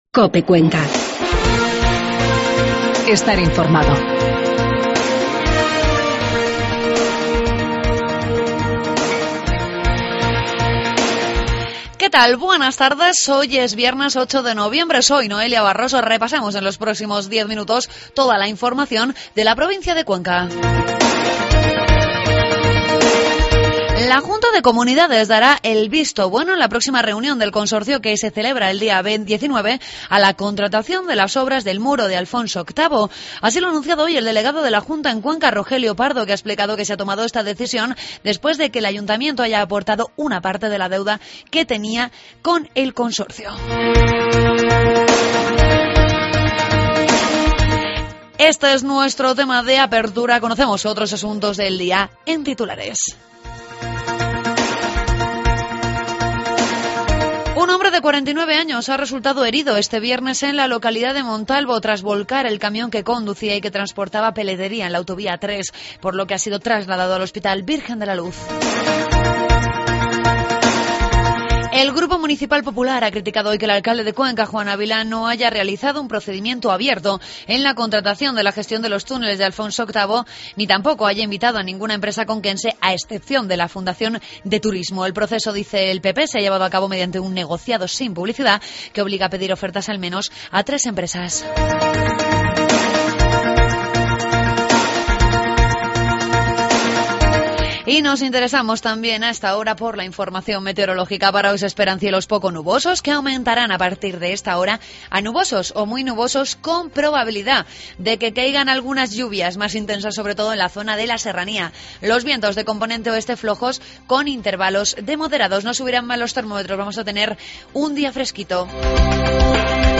Toda la información de la provincia de Cuenca en los informativos de mediodía de COPE.